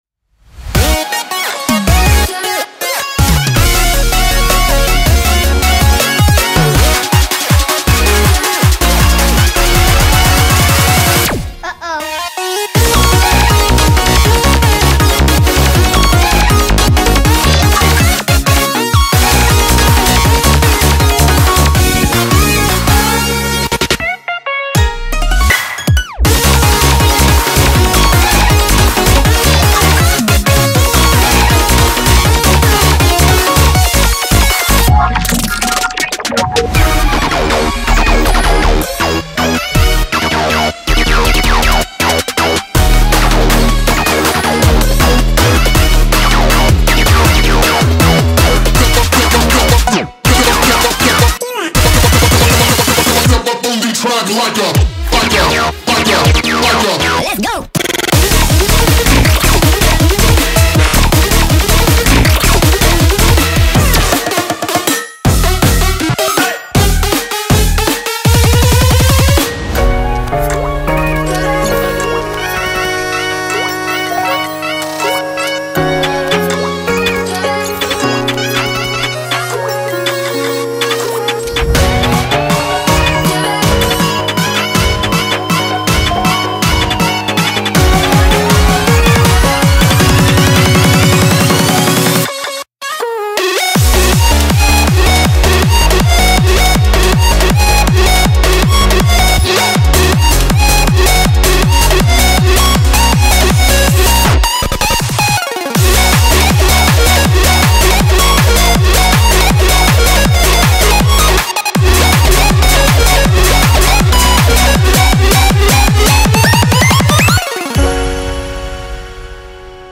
BPM160
Audio QualityPerfect (High Quality)